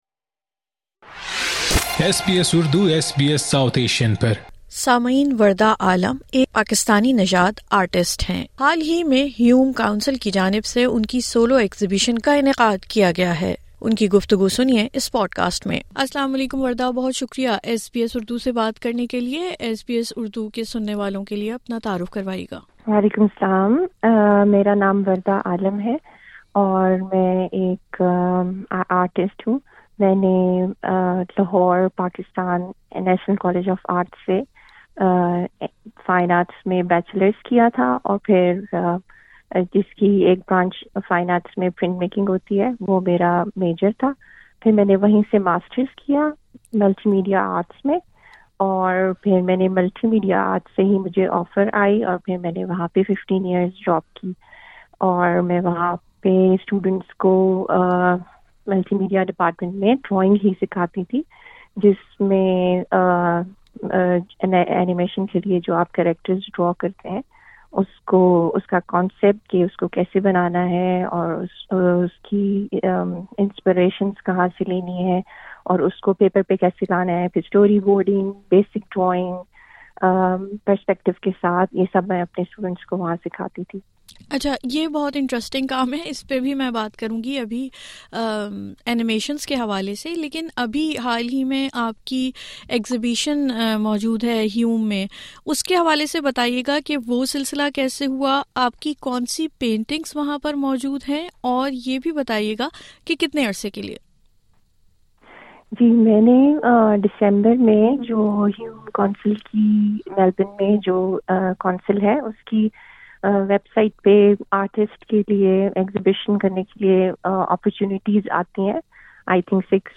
میلبرن)میں جاری ہے، ان کے شوق اور فن سے متعلق گفتگو سنئے اس پوڈکاسٹ میں ۔